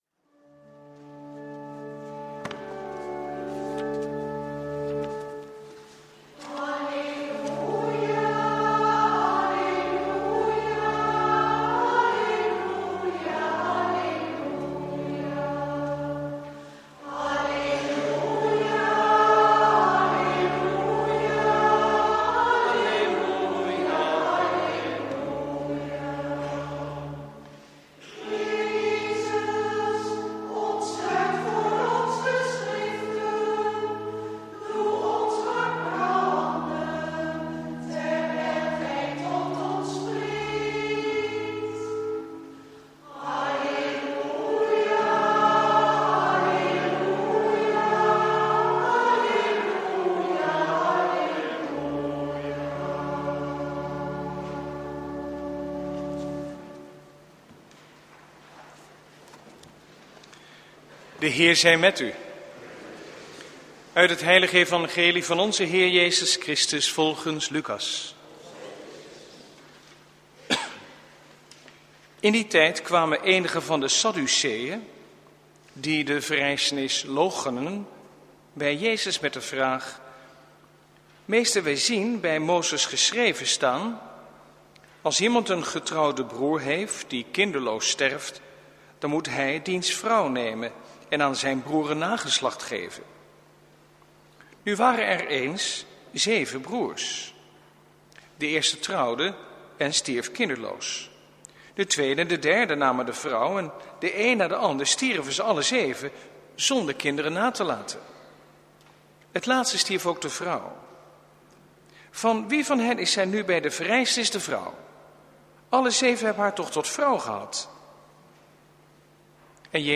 Preek 32e zondag, door het jaar C, 5/6 november 2016 | Hagenpreken
Eucharistieviering beluisteren vanuit de H. Willibrord te Oegstgeest (MP3)